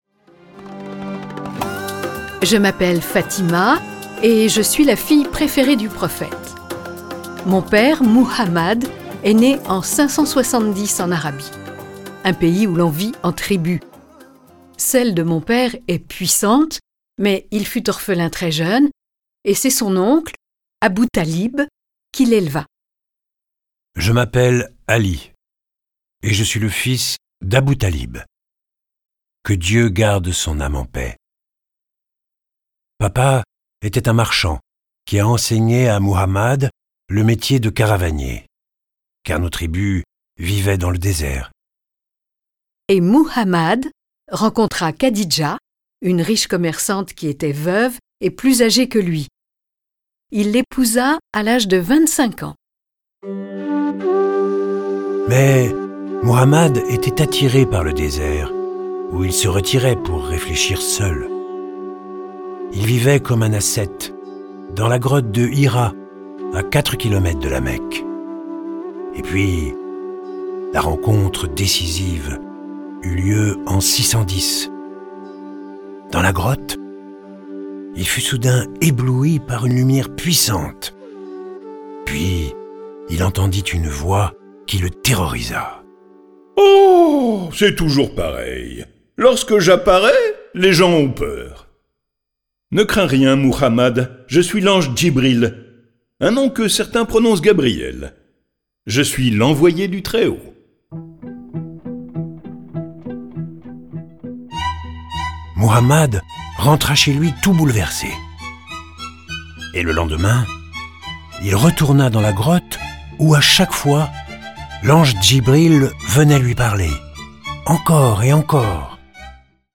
Diffusion distribution ebook et livre audio - Catalogue livres numériques
Ils décrivent aussi les 5 piliers de l’Islam que sont la profession de foi, la prière, l’aumône, le jeûne du Ramadan et le pèlerinage à La Mecque. Ce récit est animé par 6 voix et accompagné de plus de 30 morceaux de musique classique et traditionnelle.